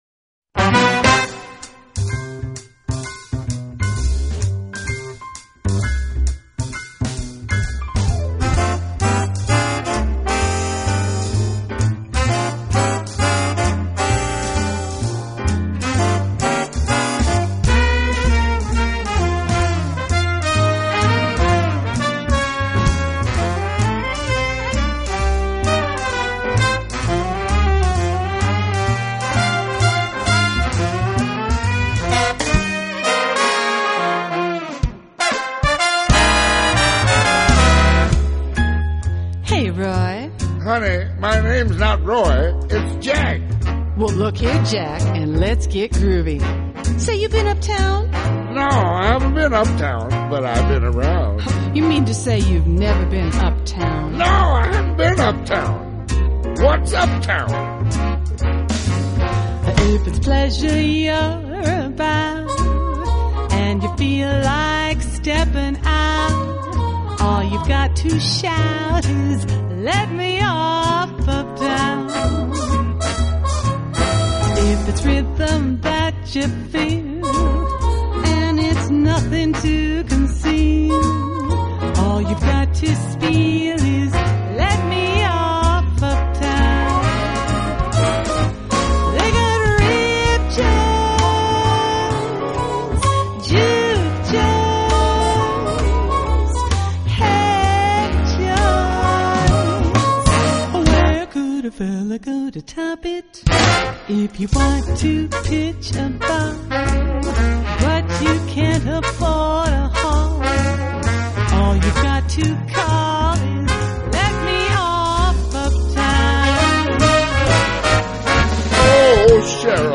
风格：Vocal Jazz General